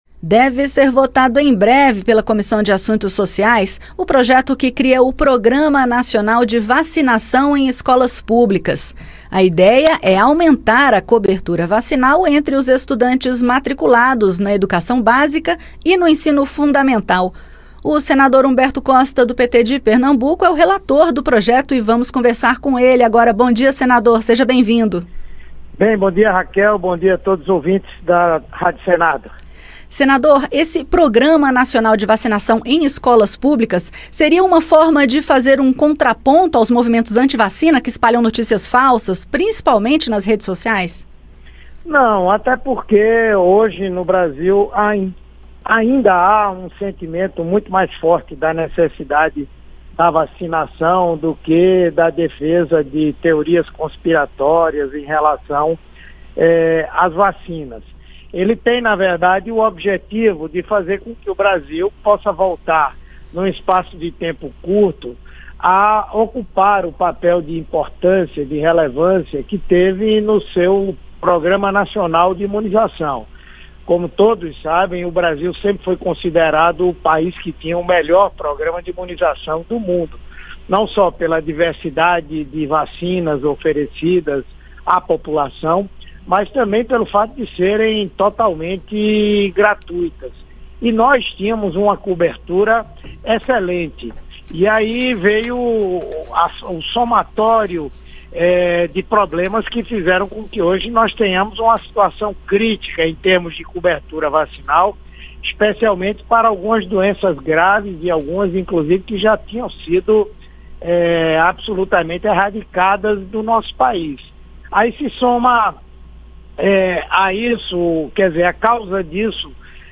O relator da proposta, senador Humberto Costa (PT-PE), falou ao Conexão Senado sobre a importância da iniciativa, especialmente no momento em que o Brasil enfrenta queda na cobertura vacinal.